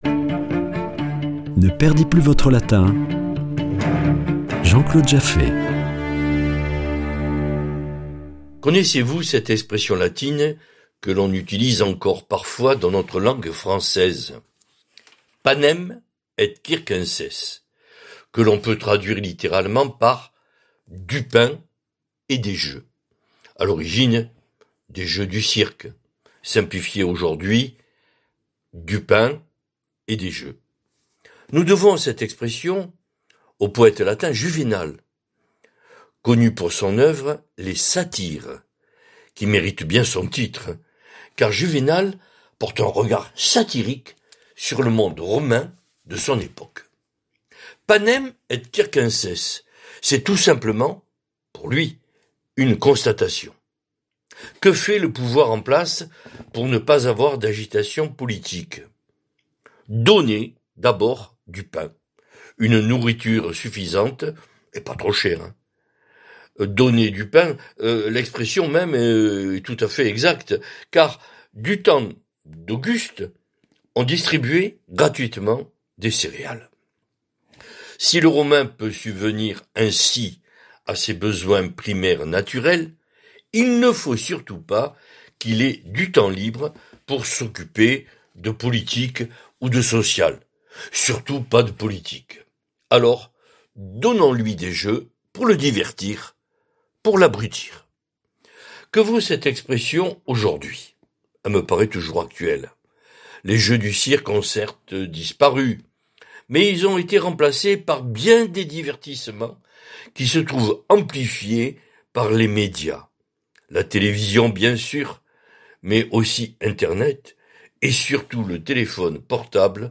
Chronique Latin